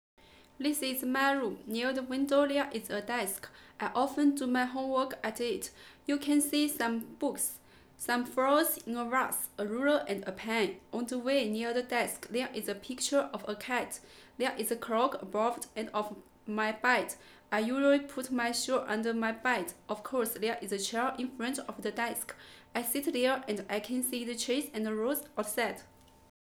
2.人声录音：
首先是5厘米左右的近距离录音，以测试话筒拾取信号的解析度；其次，是40厘米的远距离拾音，用于测试话筒的信号动态变化情况。
图16是这段语言40厘米采样的频谱图，由于拾音距离的增加，2KHz-7KHz这段频率有所衰减，尤其是3KHz的衰减，使声音亮度大幅减少。语言的清晰度随之下降，出现明显的距离感和空间感。
虽然两段采样的拾音距离相差35厘米，但其动态并没有产生变化，说明STC-3X PACK对于距离的适应感非常强，用于空间拾音不会导致信号动态损失。
Vocal-40cm.wav